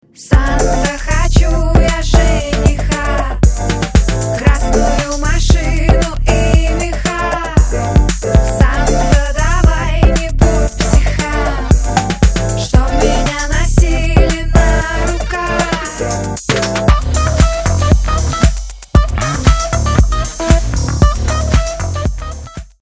поп
женский вокал
Synth Pop
dance
club
синти-поп